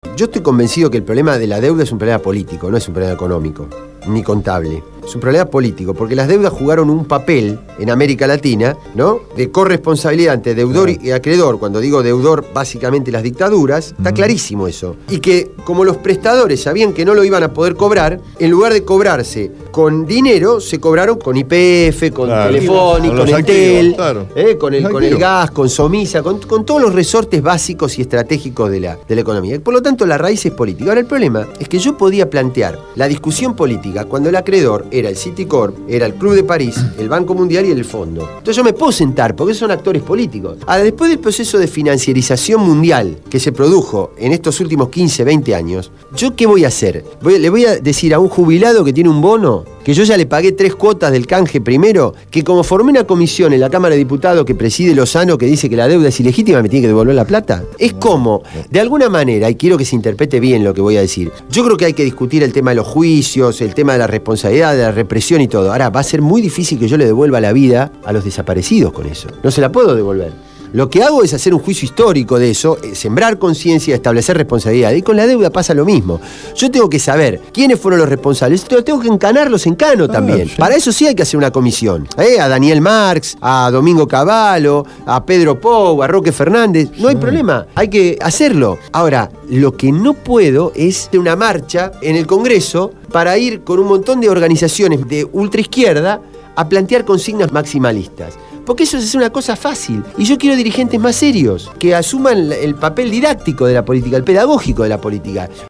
Carlos Raimundi, diputado del bloque Solidaridad e Igualdad (SI) estuvo en el piso de Radio Gráfica durante la emisión del programa «Cambio y Futuro» (Jueves, de 20 a 22 hs).